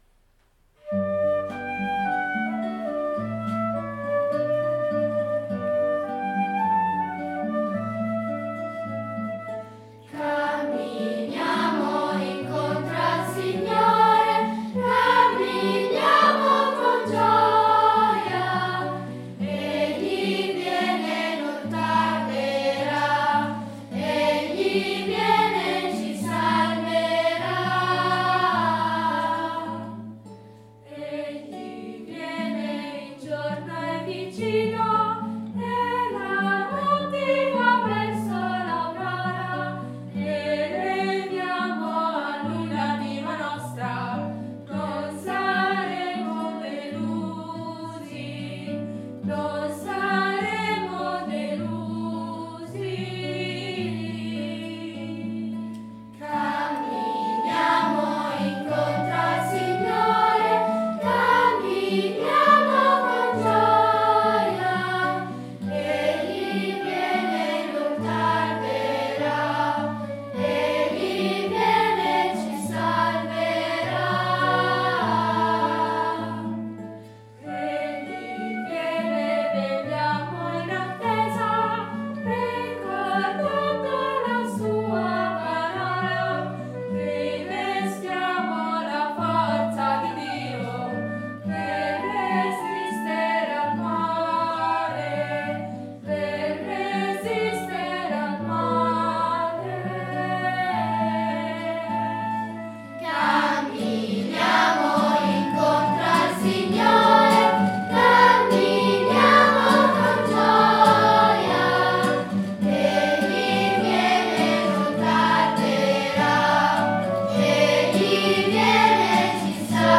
La terzina è una figura ritmica che da’ movimento al canto, gli dona un carattere non scontato e concede (se eseguita bene!) al coro e all’assemblea di lasciare andare il cuore e il corpo (è infatti adatto per le processioni d’ingresso, di offertorio e di Comunione) nell’esperienza globale del rito.
Audio voci bianche corale Arnatese